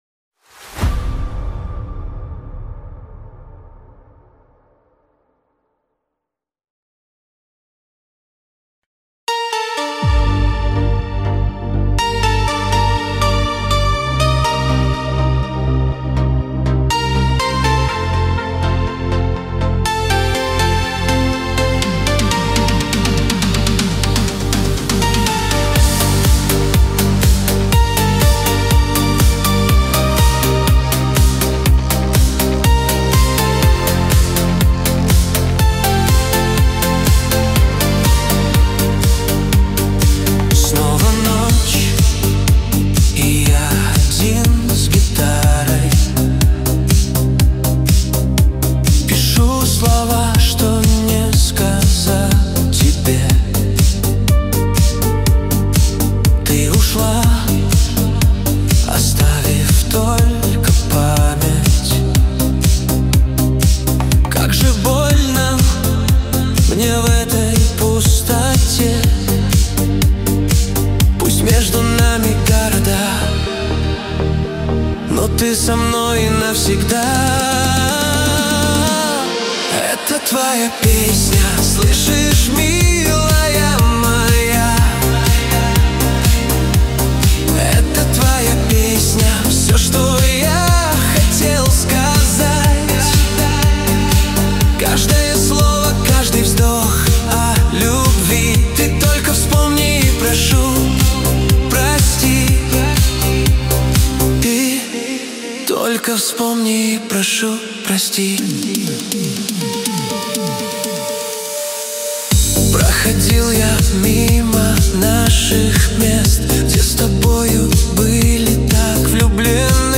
13 декабрь 2025 Русская AI музыка 85 прослушиваний